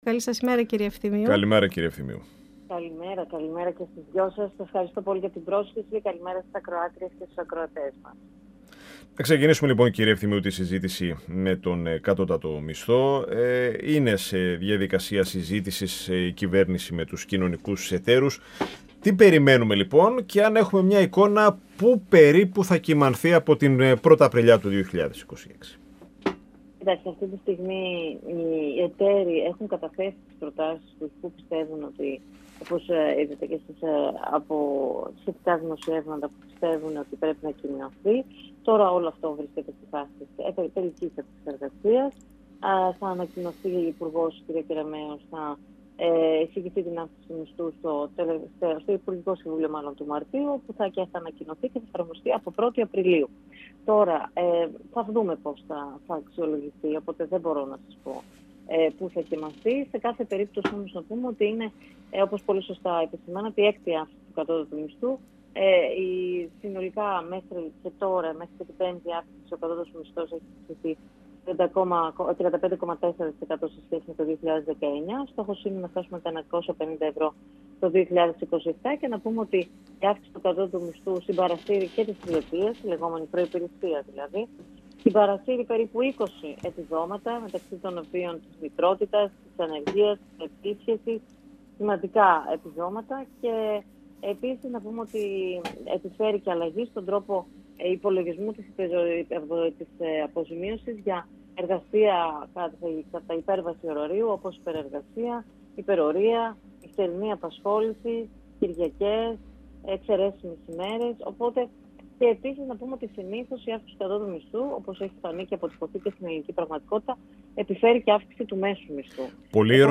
Στο δημοψήφισμα για τη ΔΕΘ, την αύξηση του κατώτατου μισθού, τις συνέπειες του πολέμου αλλά και στις συλλογικές συμβάσεις εργασίας, αναφέρθηκε μιλώντας στον 102fm, η Υφυπουργός Εργασίας και Κοινωνικής Ασφάλισης, Άννα Ευθυμίου.
Συνεντεύξεις